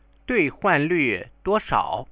You click on the Chinese characters, Chinese sentences or the headphone icons to hear the pronunciation.